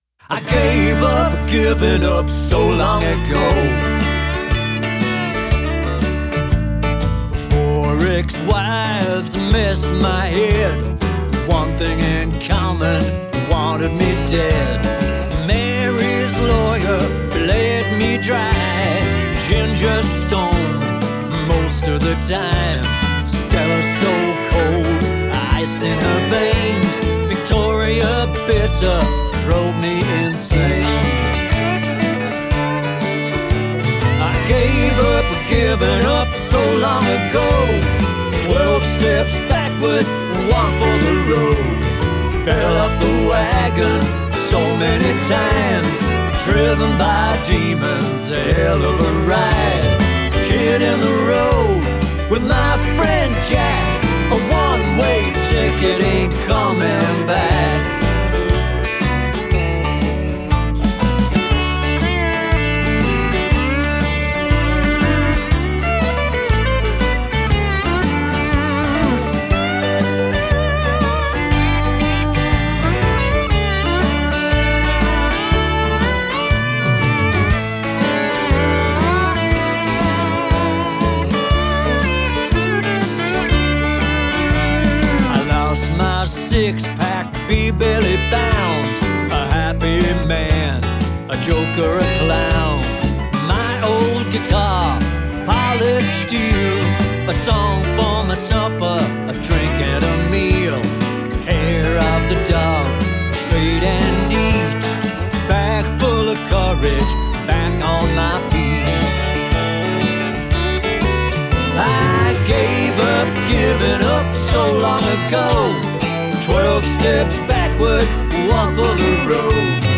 country, folk, roots group
This tune is an upbeat, tongue in cheek, honky tonk